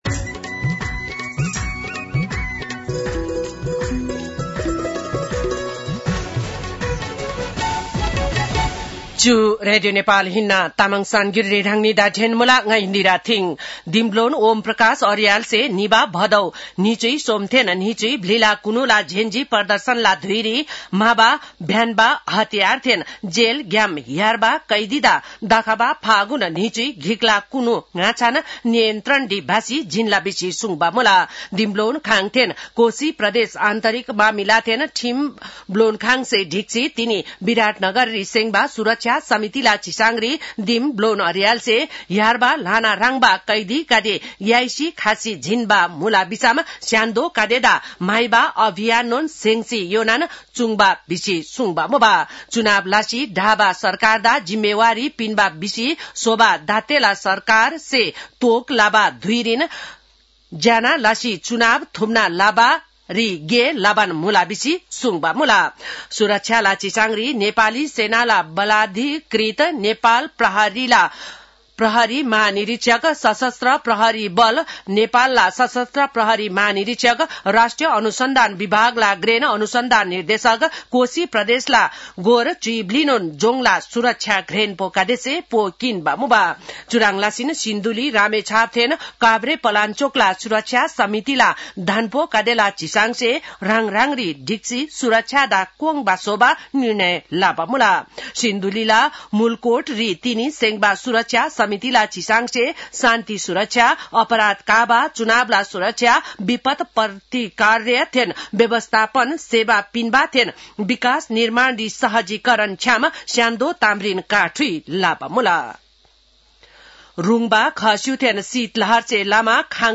तामाङ भाषाको समाचार : १६ पुष , २०८२